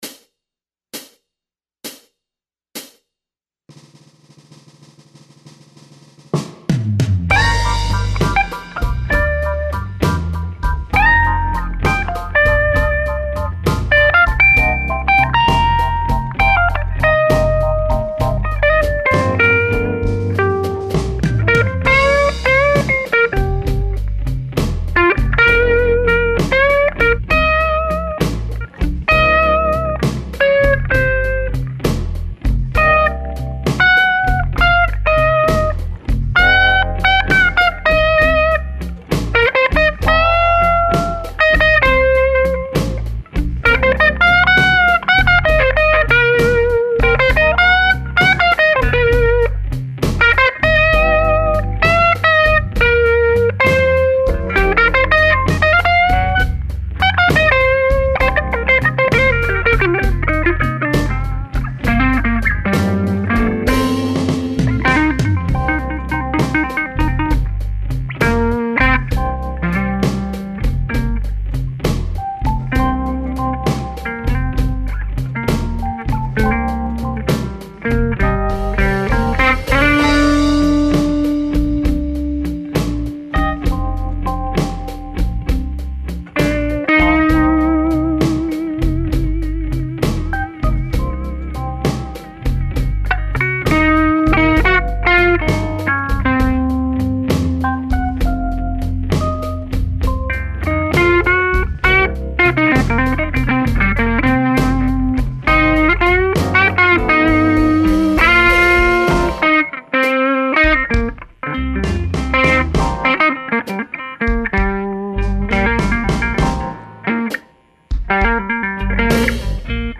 Here is a blues jam over a Guitar Center Blues Guitar Contest backing track.
I simply recorded a lead guitar part over it.
This is my crude attempt at finger style riffing on a Hamer Artist with a Seymour Duncan PAF into the 1969/70 Fender Princeton. I have a ancient 12" EV Model 12W 25watt alnico woofer in an open back extension cabinet.
The amp really opened up and starting growling and stuff once I hooked up a real speaker.
This is a straight guitar cord.. everything is on 10... no pedals etc.
I recorded it with a pair of SM57s in X/Y into a Great River MP-2NV.
I mixed the guitar hot and in your face just to highlight the really straight forward tube distortion tone when it's pushing hard into a speaker that is acting springy and ringy.
A Slow Blues 04a.mp3